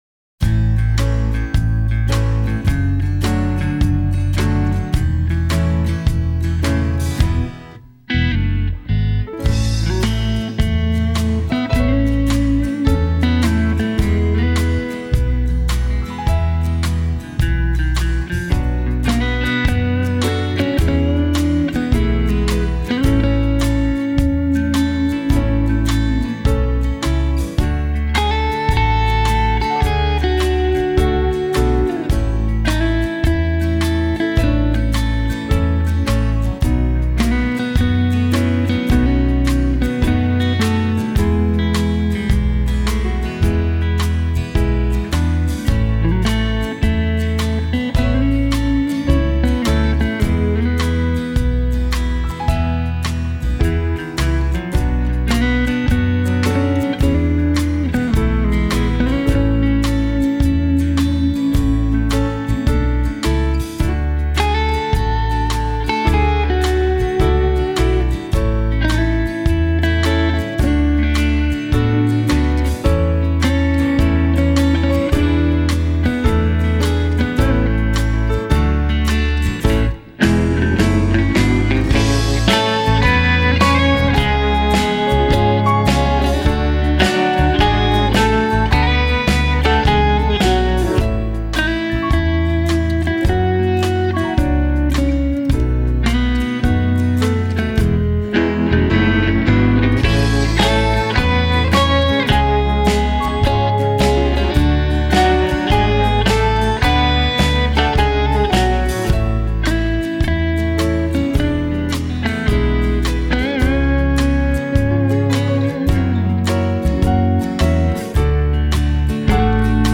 浪漫动人的经典乡村情歌以纯音乐的形式为你深情演绎！